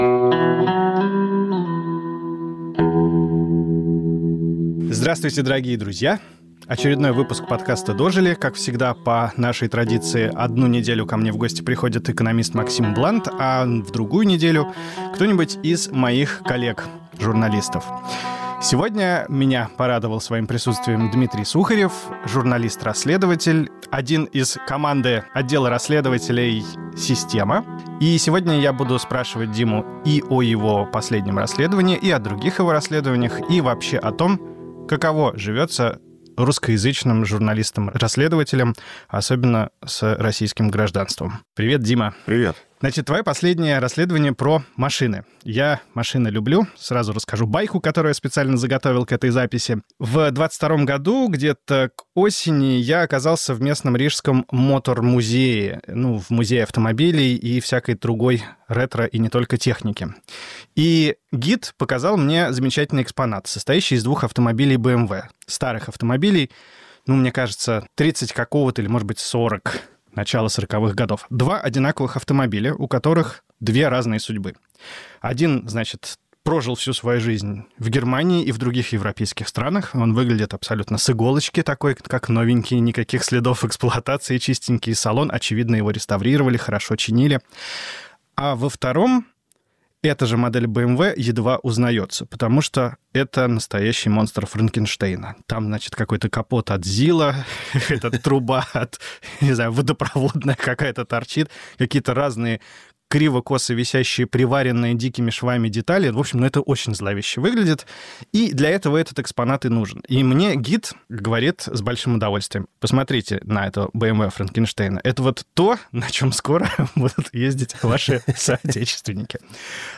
Повтор эфира от 15 марта 2025 года.